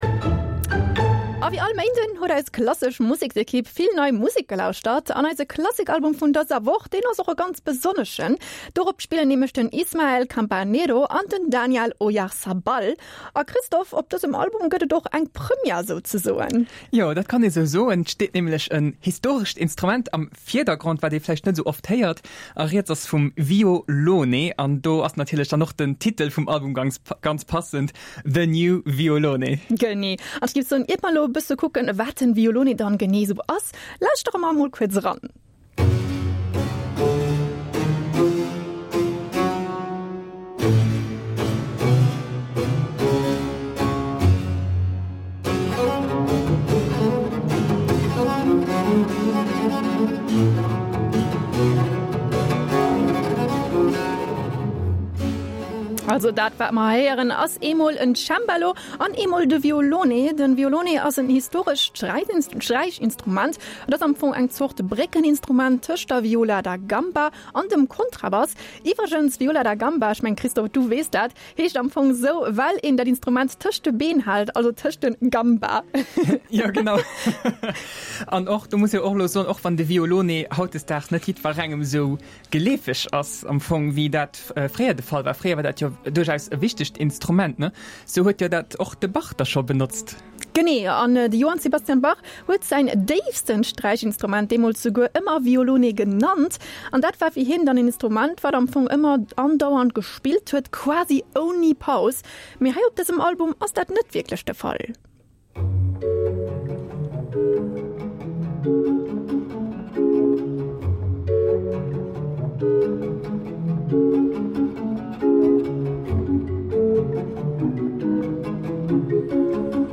Réckbléck: Klassik-Album
D’Resultat: ee rockege Barock, oder einfach Ba-Rock. Alles an allem ass dëst een Album, dee voller Energie stécht an dee sech och vun anere Barock-Alben däitlech ënnerscheed.